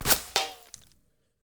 main Divergent / mods / JSRS Sound Mod / gamedata / sounds / material / bullet / collide / dirt04gr.ogg 47 KiB (Stored with Git LFS) Raw Permalink History